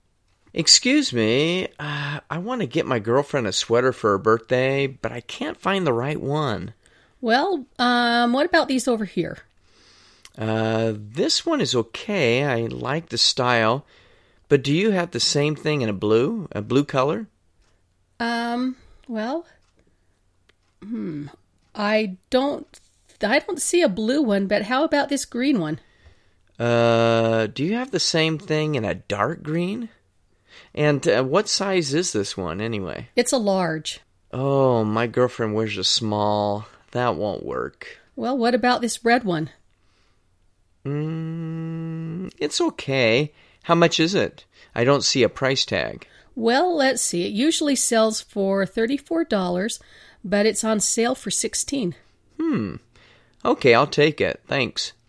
【听英文对话做选择】新衣服 听力文件下载—在线英语听力室